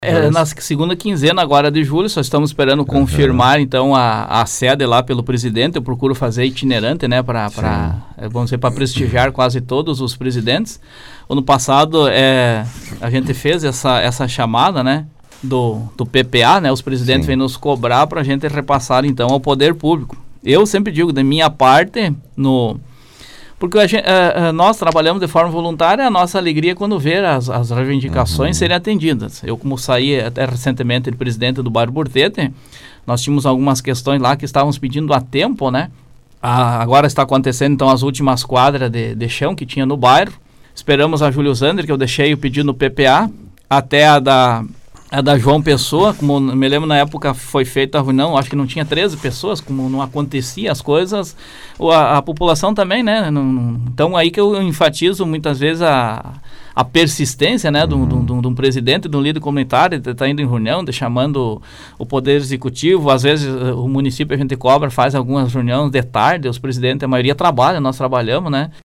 entrevista
Outras questões foram tratadas pelos dirigentes com contribuições também dos ouvintes. A construção de uma nova unidade de saúde no bairro Mundstock também esteve na pauta da entrevista.